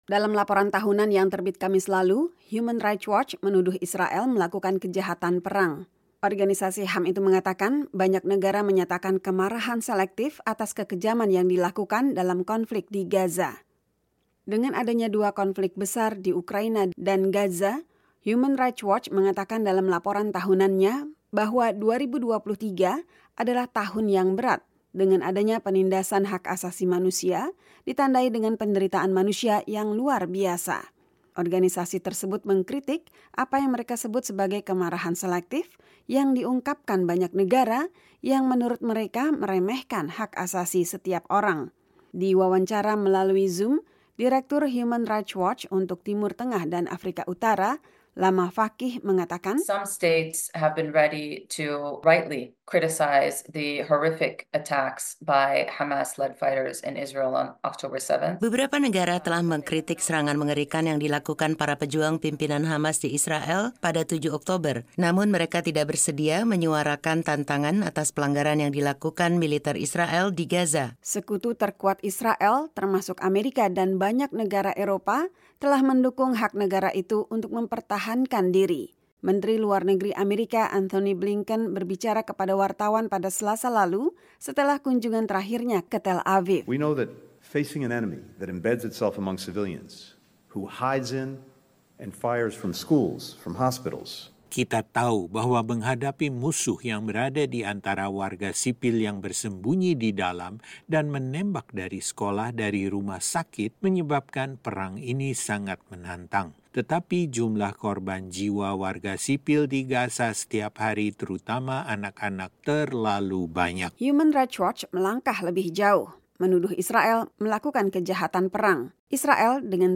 Laporan Human Rights Watch Tuduh Israel Lakukan Kejahatan Perang